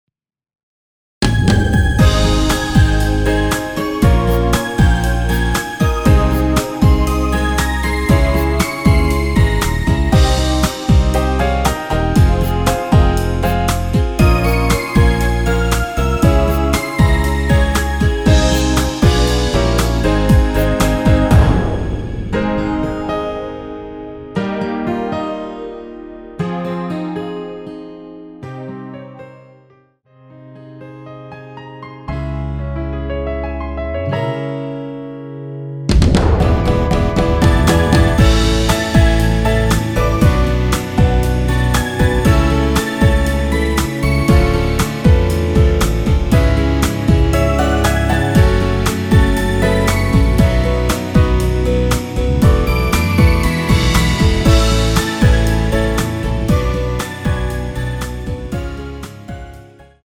원키에서(+5)올린 MR입니다.
Ab
앞부분30초, 뒷부분30초씩 편집해서 올려 드리고 있습니다.